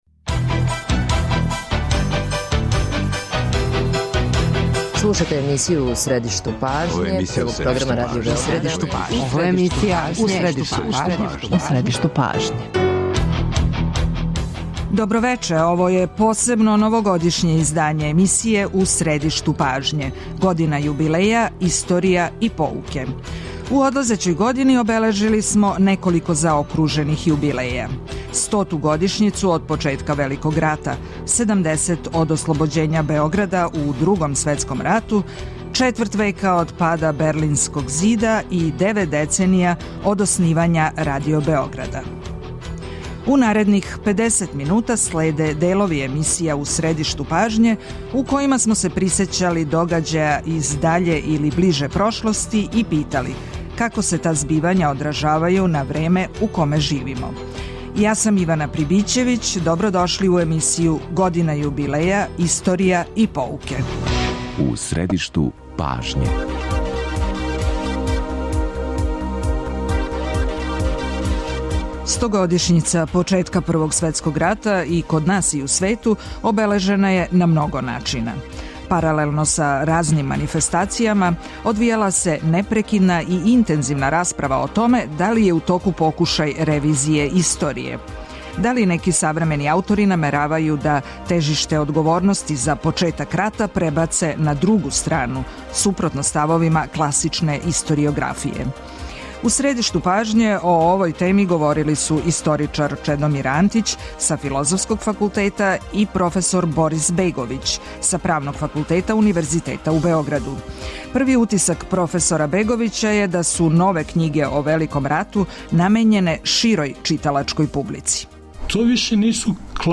Чућете делове емисија 'У средишту пажње' у којима смо пратили важне јубилеје и питали - како су се догађаји из ближе или даље прошлости одразили на време у коме живимо?